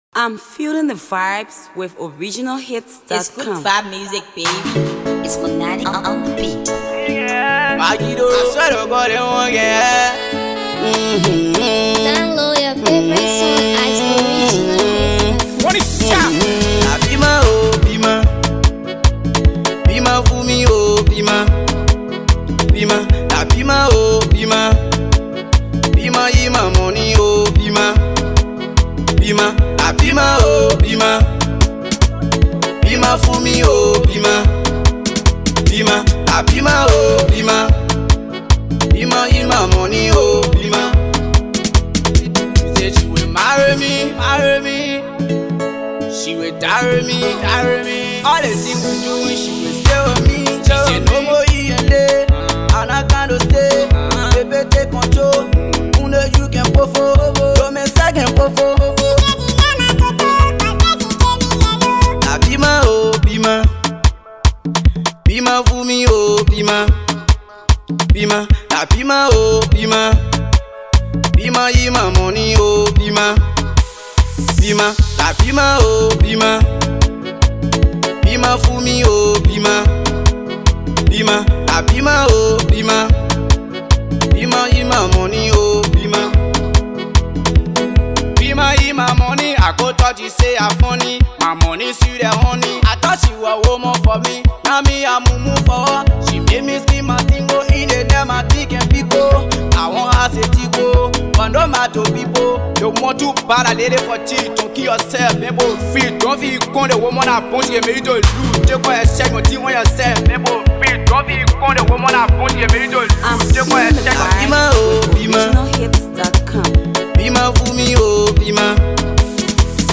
it’s a nice danceable banger.